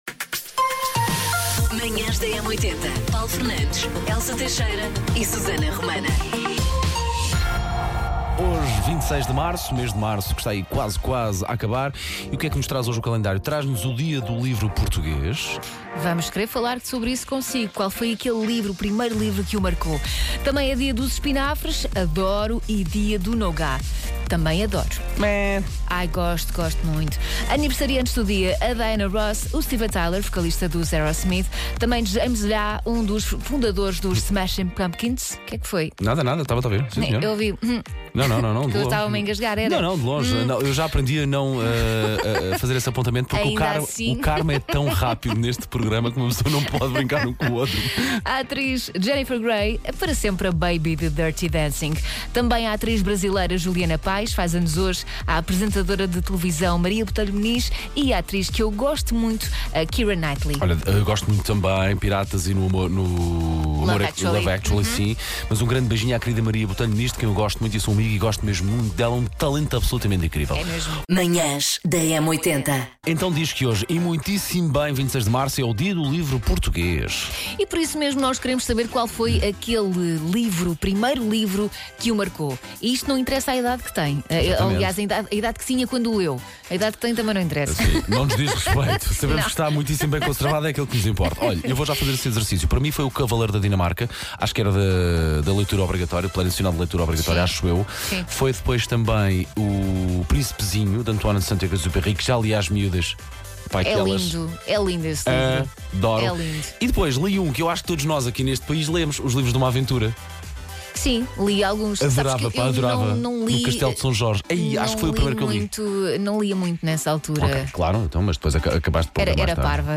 … continue reading 2831 episode # Conversas # Sociedade # Portugal # Manhãs Da M80